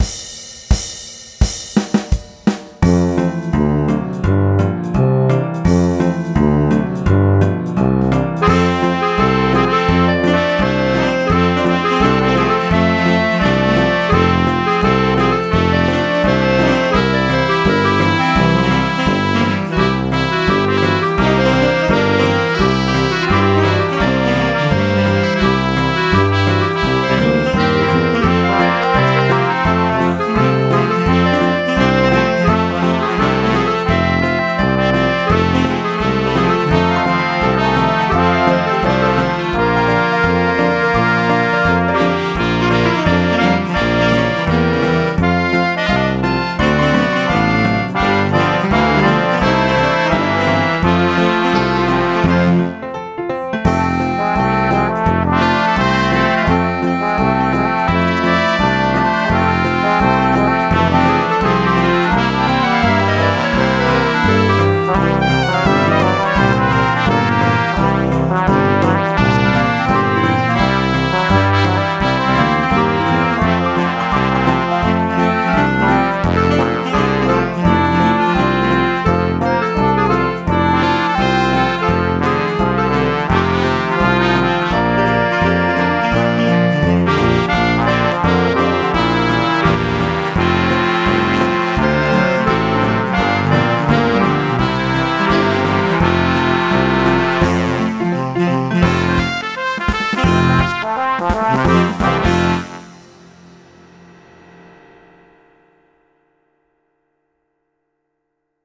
(In orchestration. No video.)